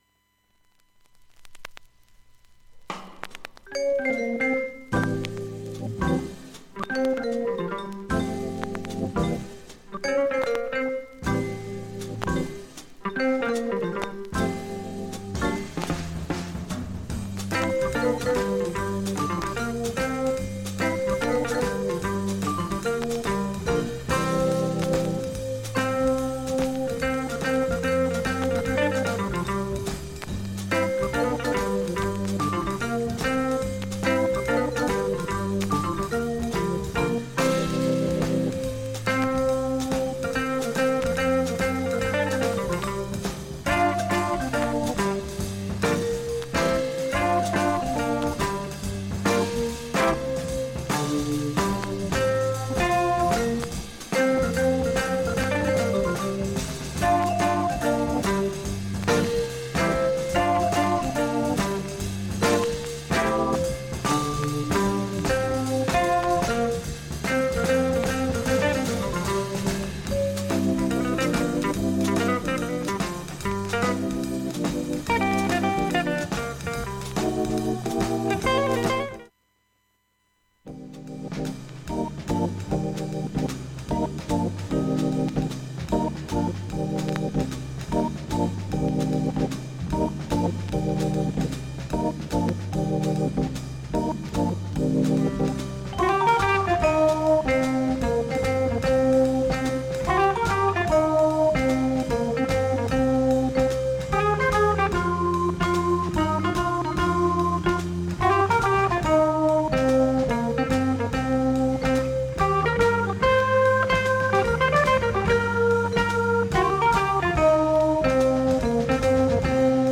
音質良好全曲試聴済み。
75秒の間にプツッという感じの
周回プツ音が出ます。
音自体は小さい目です。
現物の試聴（上記録音時間8分弱）できます。音質目安にどうぞ
ほか3回までのわずかなプツ6箇所
単発のわずかなプツ10箇所
クールなヴィブラフォンも随所で冴え渡る
異色の編成